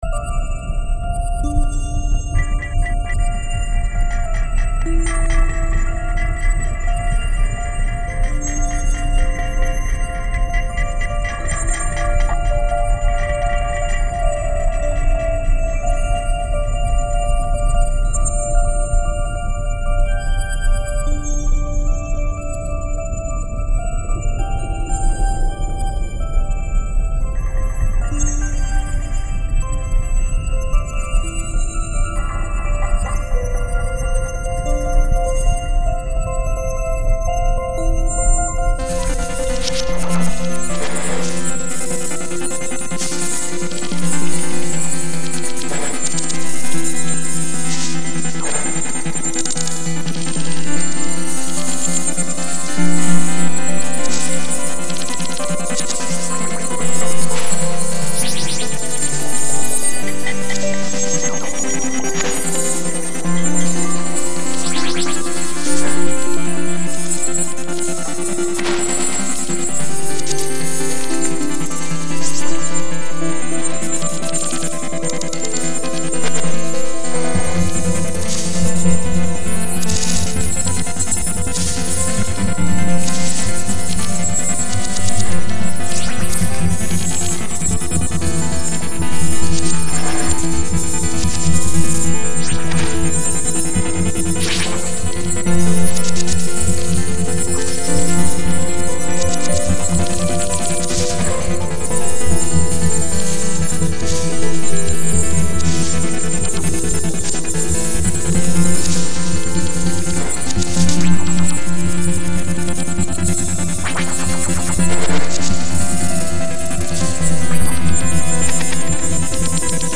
influenced by Nu-Jazz, Broken Beat, Techno and Ambient.